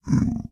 Sound / Minecraft / mob / zombiepig / zpig3.ogg
should be correct audio levels.